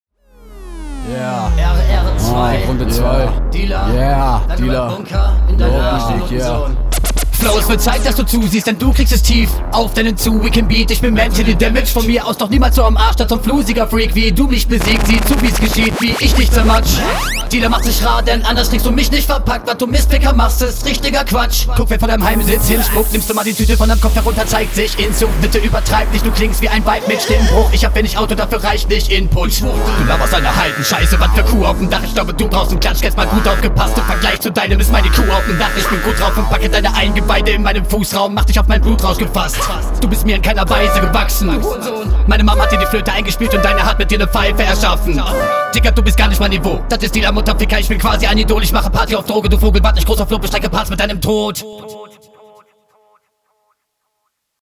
Kommst sehr lässig rein, ersten Lines sind gut gerappt aber keine wirklich nenneswerte Aussage drin.